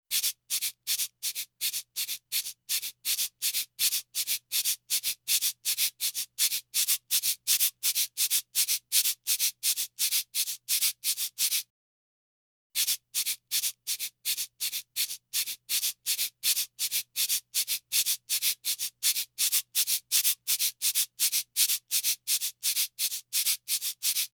Audio test: Localizzazione spaziale del suono
sinistra -> destra
05-audiocheck.net_LEDR_Lateral.wav